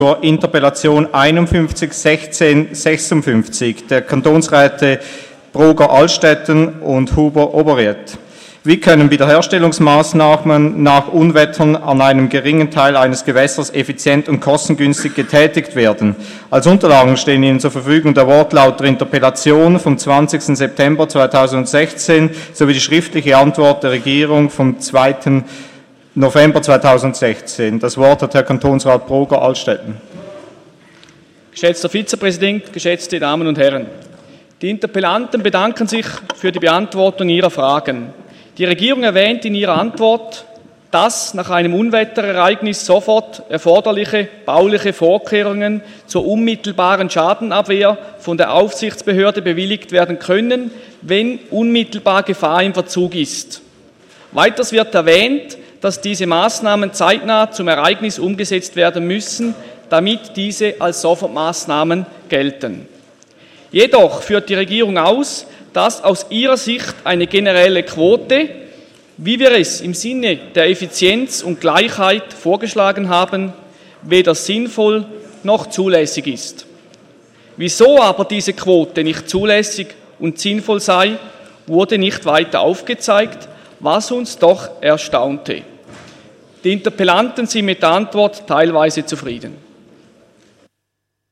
29.11.2016Wortmeldung
Session des Kantonsrates vom 28. und 29. November 2016